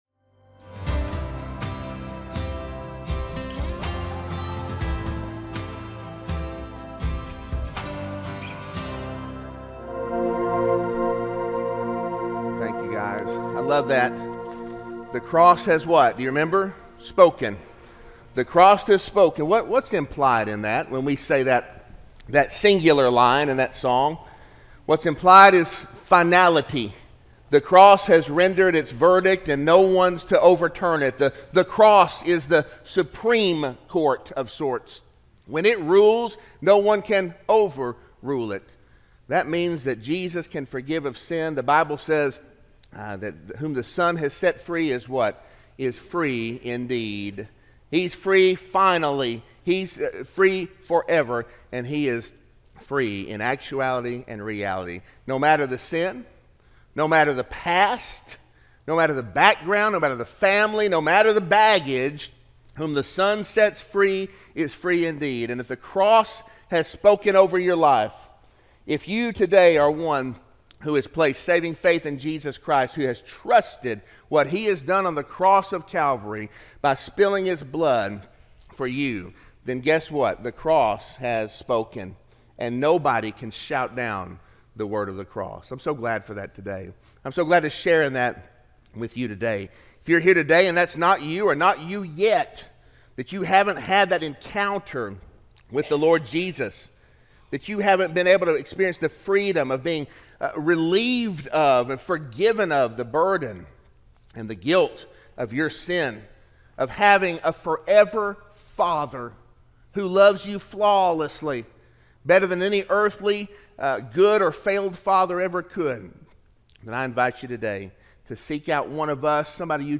Sermon-6-9-24-audio-from-video.mp3